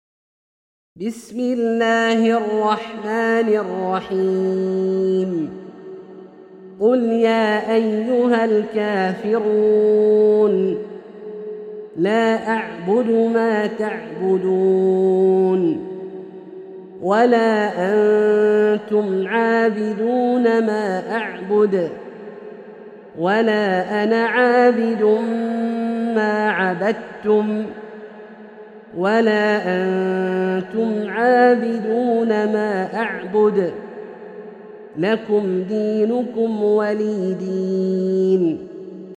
سورة الكافرون - برواية الدوري عن أبي عمرو البصري > مصحف برواية الدوري عن أبي عمرو البصري > المصحف - تلاوات عبدالله الجهني